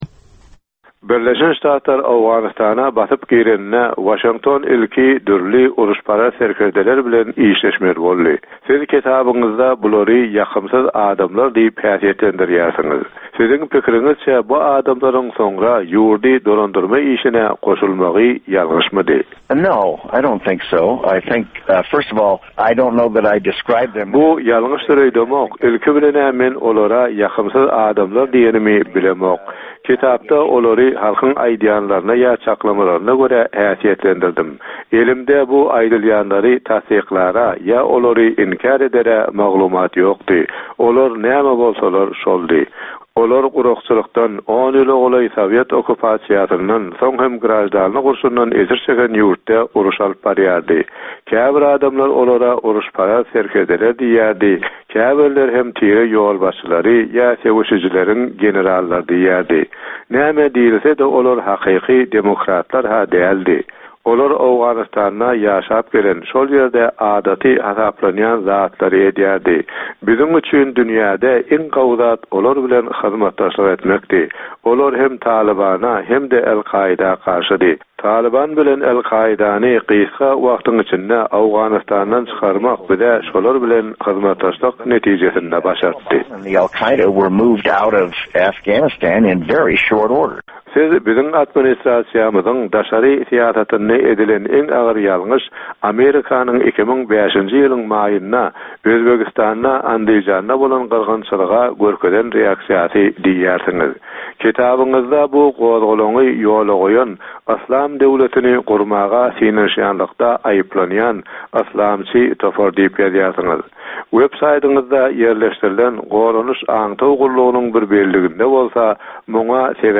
ABŞ-nyň öňki goranmak ministri bilen söhbet